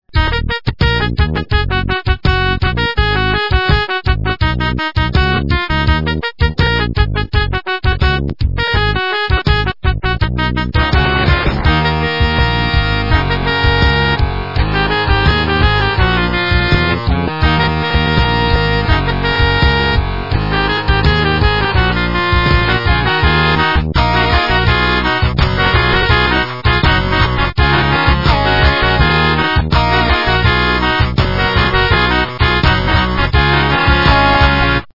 - западная эстрада
полифоническую мелодию